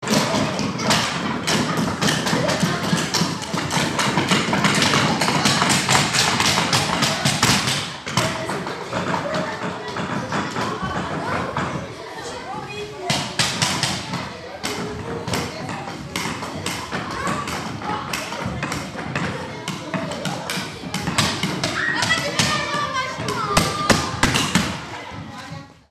la salle de musique
C’est la fin du cours de musique. Les élèves tapent sur le sol avec des bâtons.
[En se promenant dans notre école, voici ce que l’on peut entendre… ]   Details »
lasalledemusique.mp3